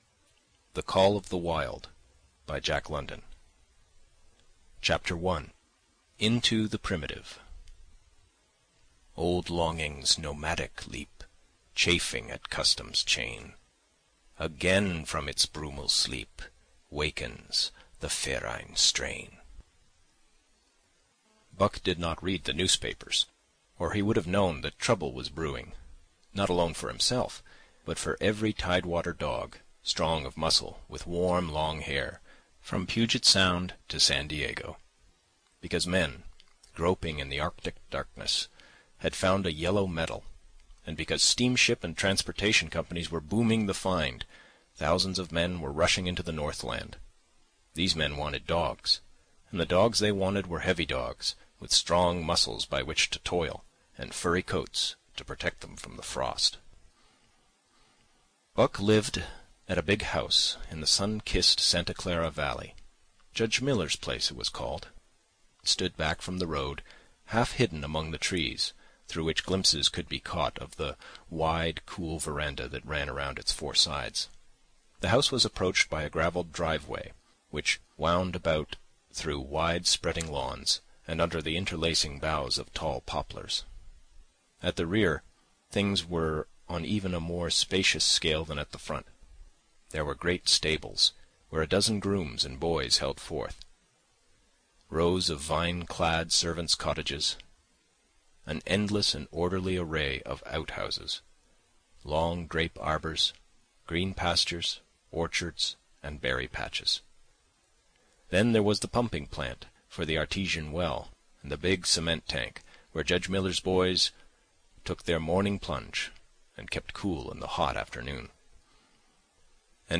The Call of the Wild by Jack London ~ Full Audiobook [adventure]